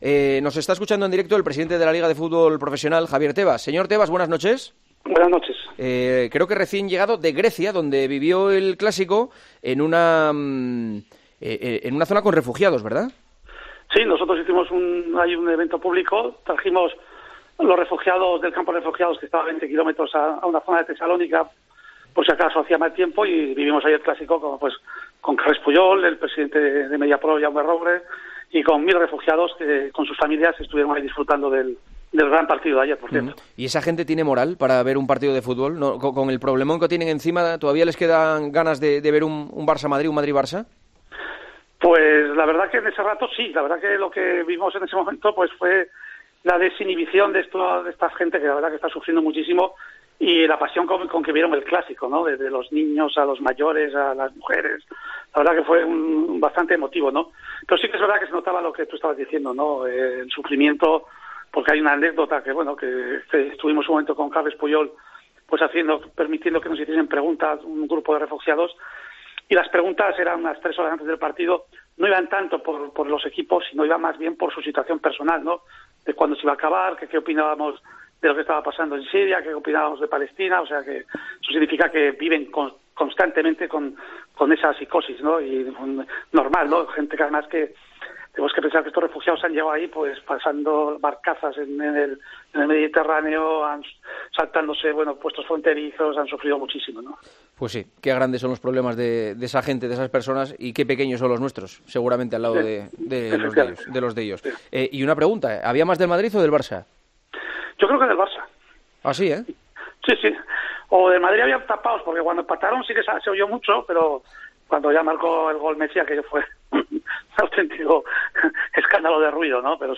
Recién llegado de Grecia, de ver el Clásico en un acto al que invitaron a un millar de refugiados, el presidente de LaLiga atendió la llamada de El Partidazo de COPE para hablar de la actualidad del fútbol español.